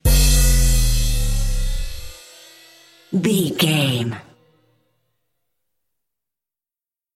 Ionian/Major
drum machine
synthesiser